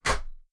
toasterstep3.wav